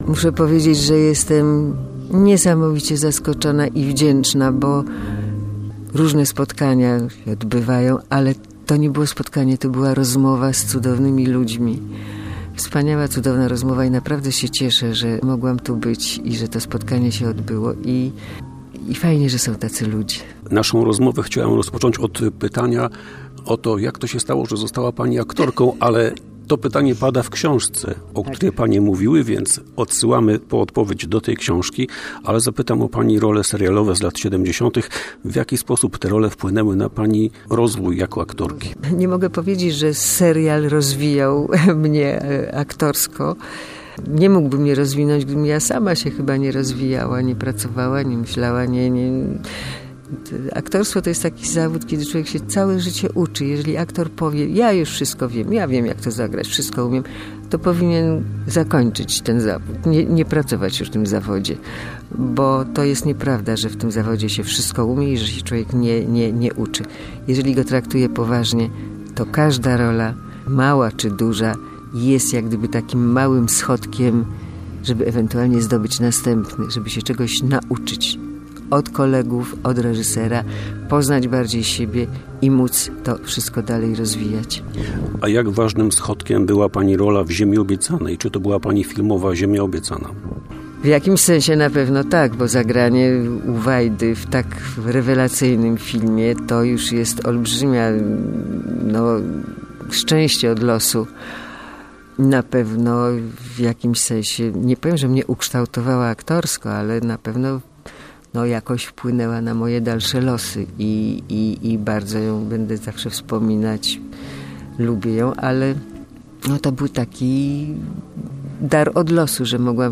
Zapis rozmowy: